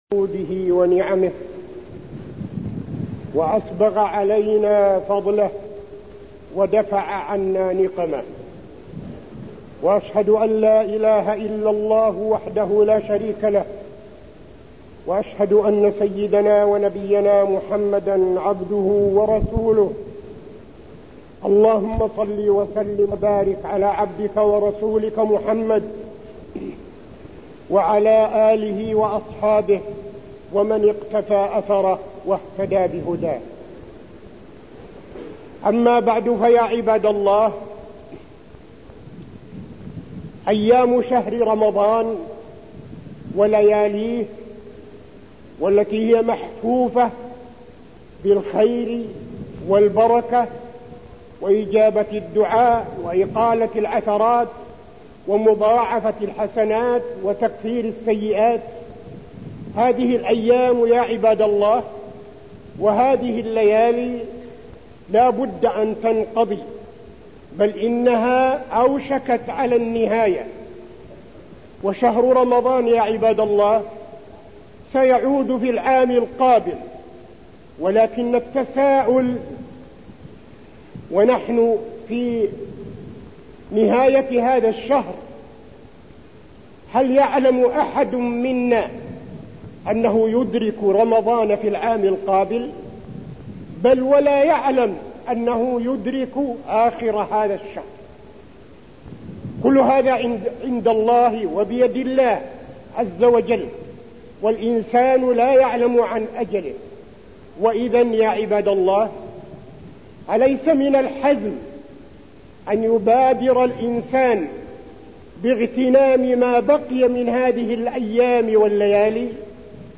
تاريخ النشر ٢٥ رمضان ١٤٠٢ المكان: المسجد النبوي الشيخ: فضيلة الشيخ عبدالعزيز بن صالح فضيلة الشيخ عبدالعزيز بن صالح ليلة القدر